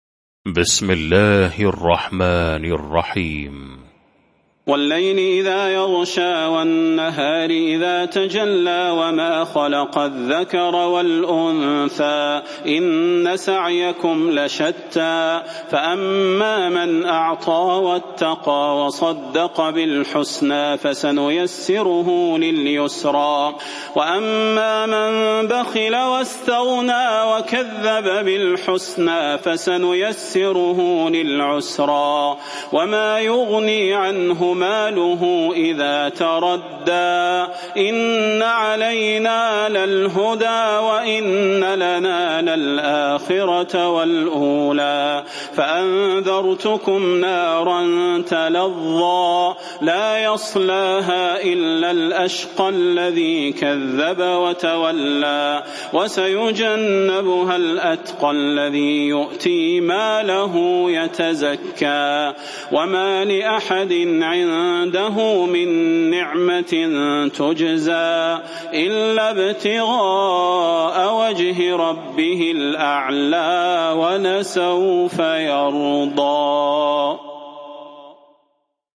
المكان: المسجد النبوي الشيخ: فضيلة الشيخ د. صلاح بن محمد البدير فضيلة الشيخ د. صلاح بن محمد البدير الليل